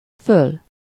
Ääntäminen
Tuntematon aksentti: IPA: /ˈføl/